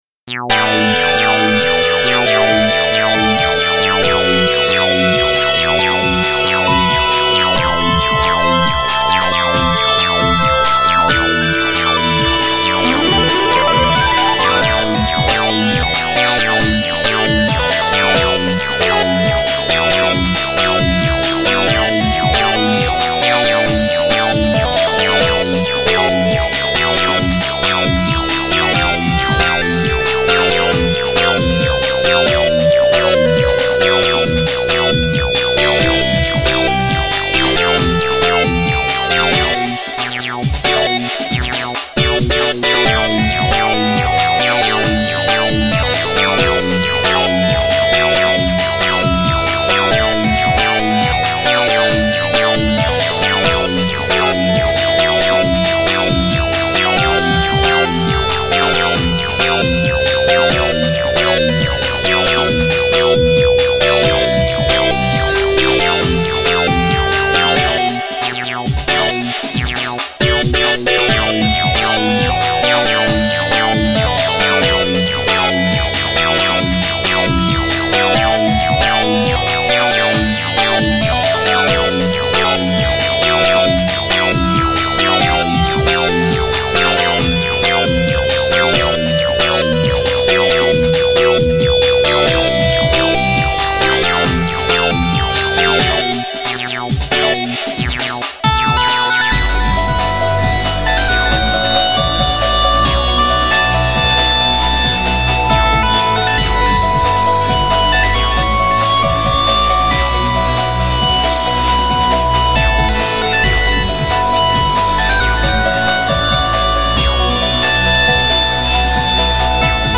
Another DnB Remix)file